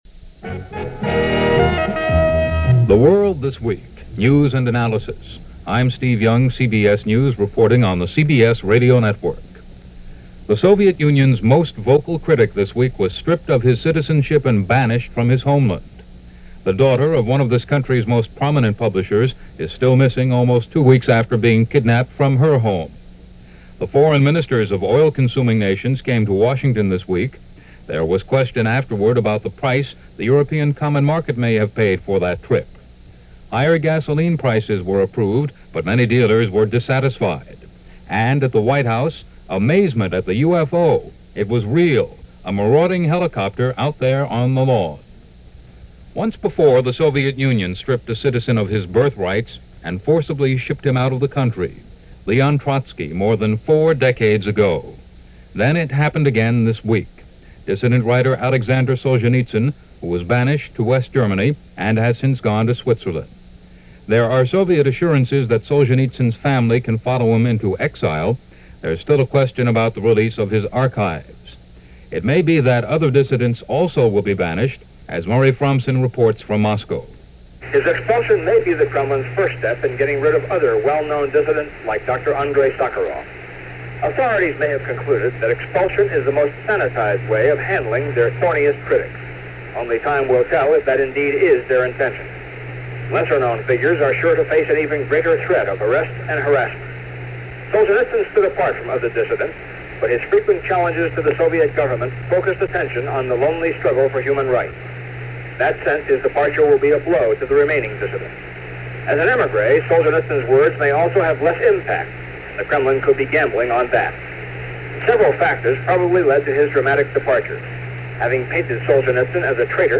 February 16, 1974 – CBS Radio: The World This Week – Gordon Skene Sound Collection –
But it was part of the events reported by CBS Radio’ The World This Week.